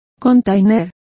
Complete with pronunciation of the translation of containers.